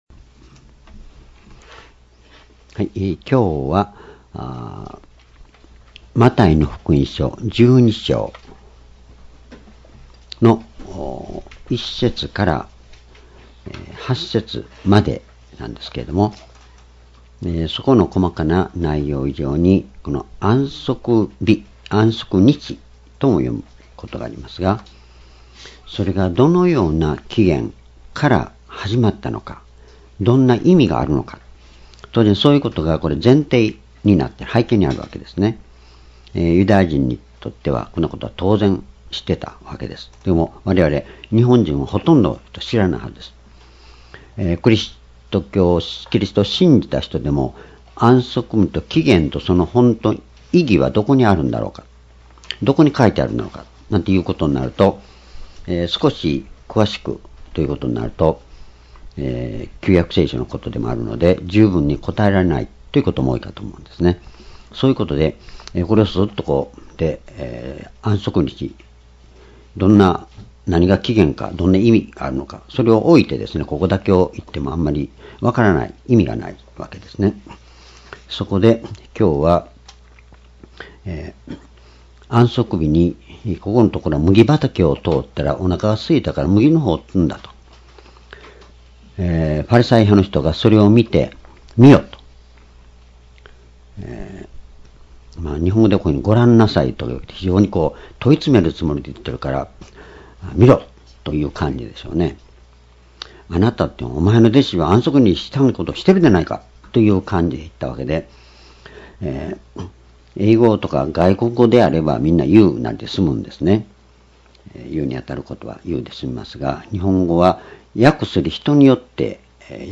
講話 ｢安息日の歴史と､その重要性｣ マタイ福音書12章1節-8節 2017年09月17日 主日